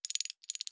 SFX_Unscrew_02.wav